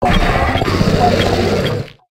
ironjugulis_ambient.ogg